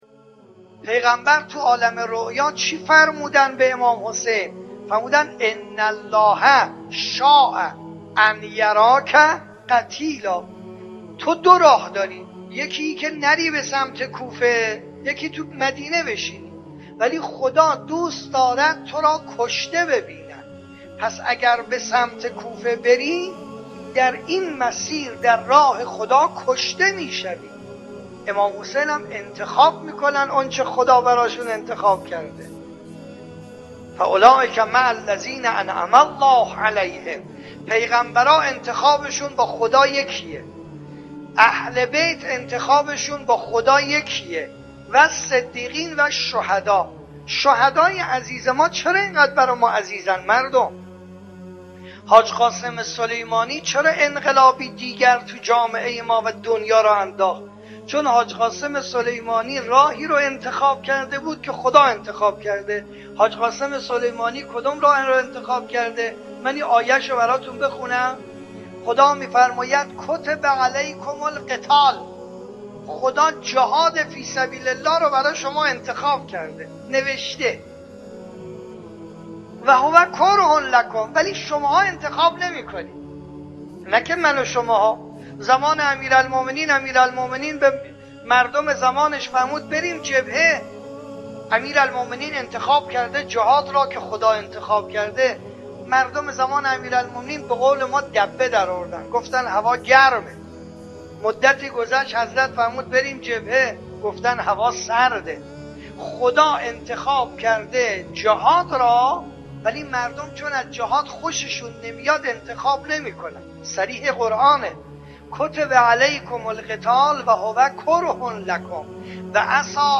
مجموعه پادکست «جلوه‌ای از بندگی» با کلام اساتید به نام اخلاق به کوشش ایکنا گردآوری و تهیه شده است